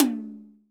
Percs
NR_TOM_L.WAV